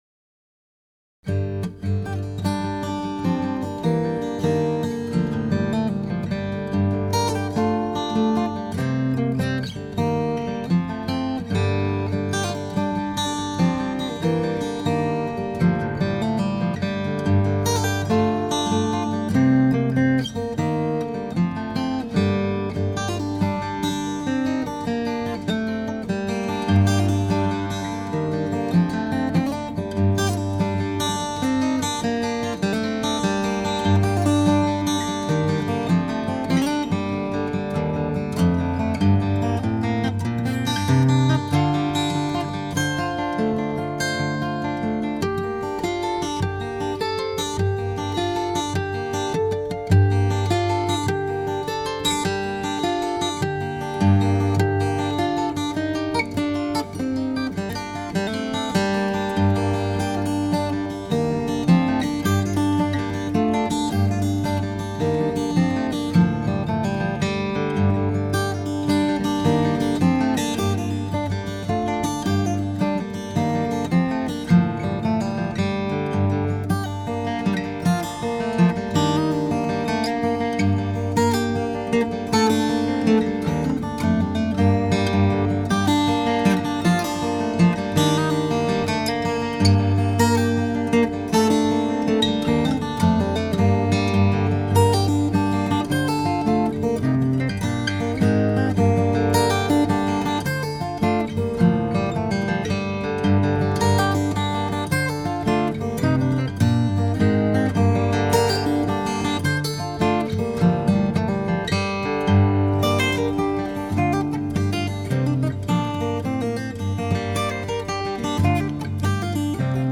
挥洒着传统凯尔特音乐的色彩
他的作品处处体现了爱尔兰民族风格